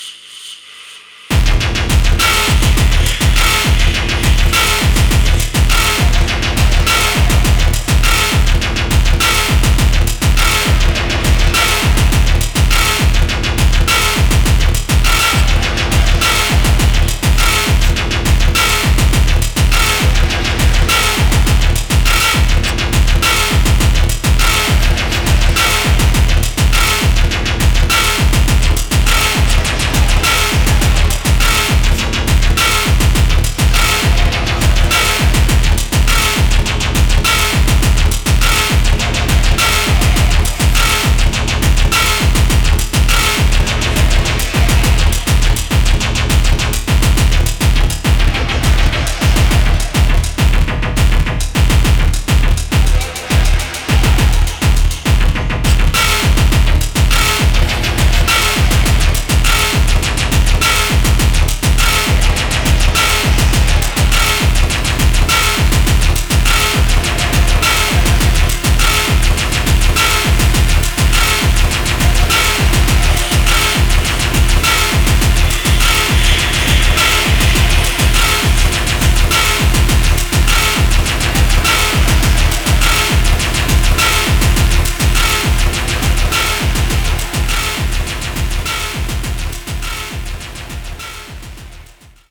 Techno Wave